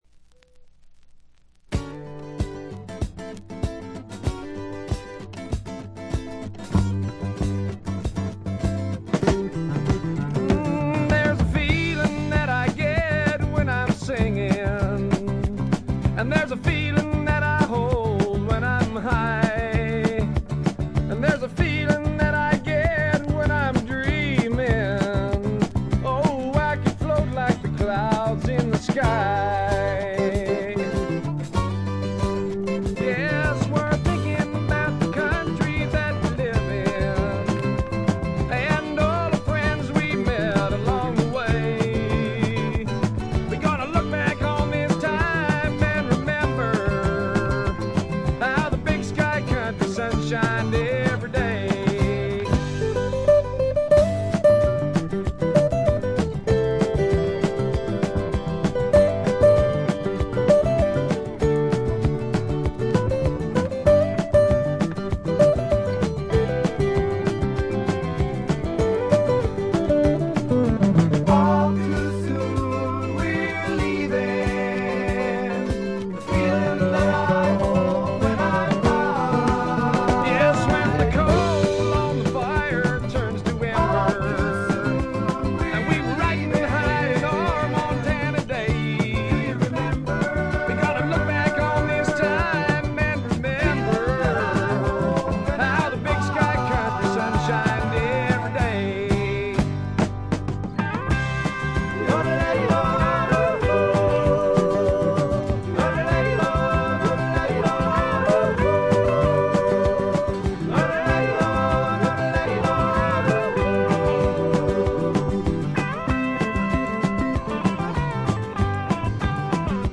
チリプチ多め、散発的なプツ音も少々出ますが、普通に鑑賞できるものと思います。
知名度はいまいちながら実力派のいぶし銀のカントリーロックを聴かせます。
試聴曲は現品からの取り込み音源です。